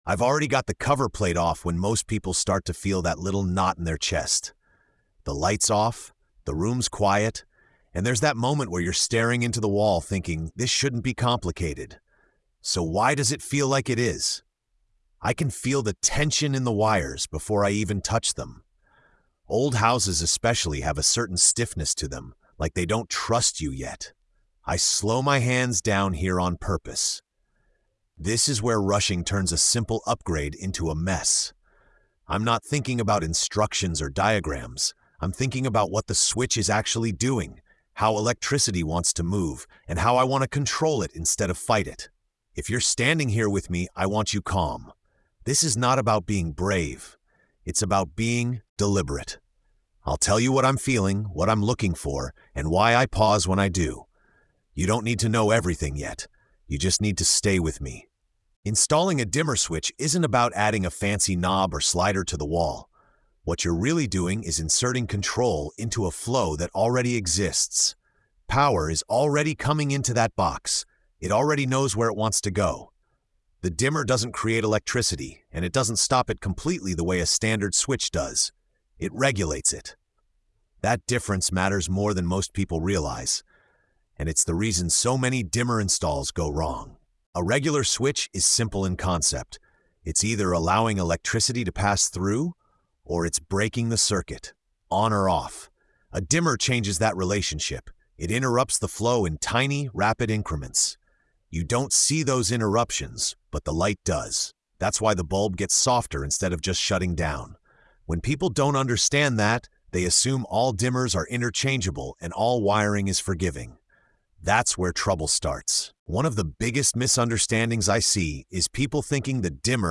The episode focuses on understanding how electricity behaves, why dimmers are different from ordinary switches, and how confidence is built through deliberate, thoughtful work. Calm, grounded, and reassuring, the story transforms a common DIY fear into a controlled, empowering experience.